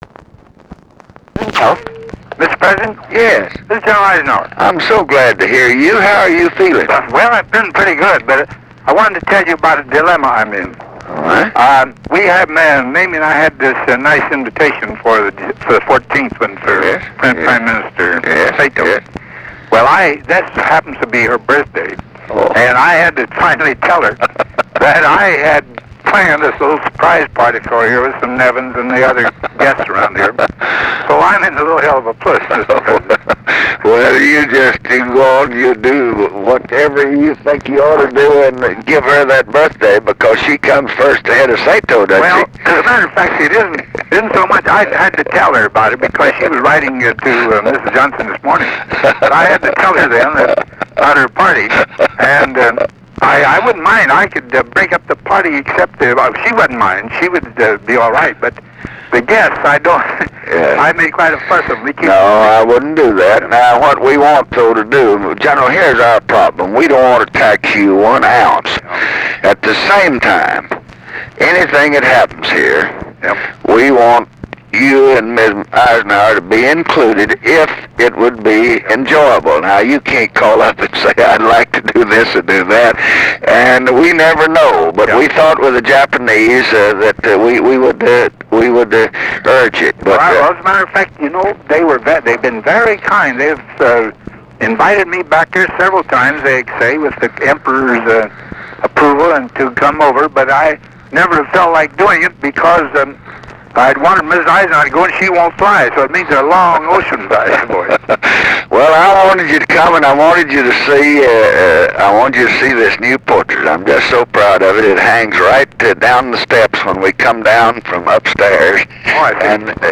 Conversation with DWIGHT EISENHOWER, November 4, 1967
Secret White House Tapes